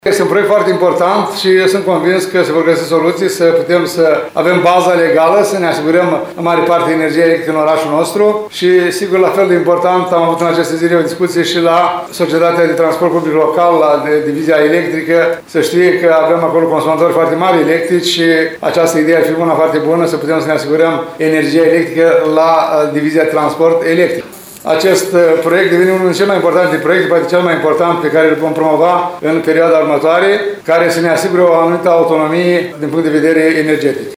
Primarul ION LUNGU a precizat astăzi că a fost depusă o ofertă pentru derularea acestui proiect de către o firmă din Cluj Napoca.